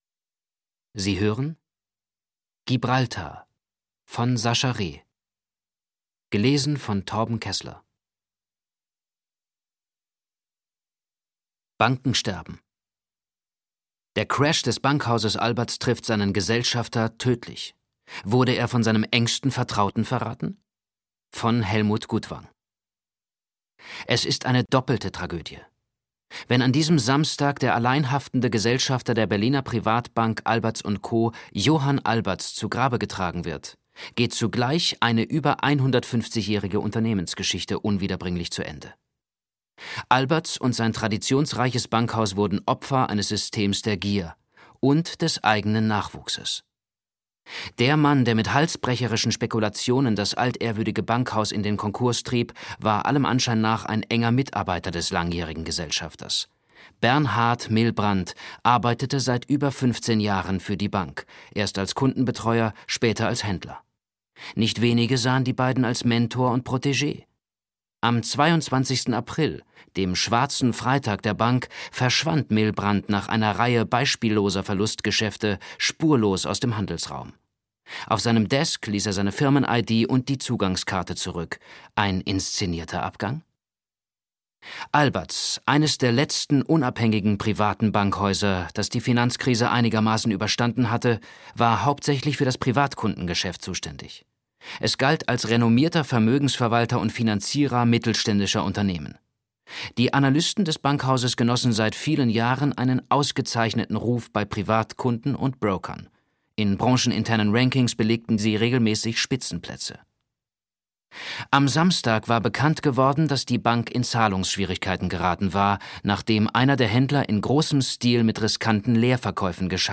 Schlagworte Banker • Flucht • Geld • Gier • Hörbuch • Hörbuch; Literaturlesung • Illegalität • Investment Banking; Roman/Erzählung • Konsequenzen • Lesung • Offshore • Schuld • Schulden • Spanien • Versteck • Wirtschaft